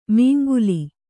♪ mīnguli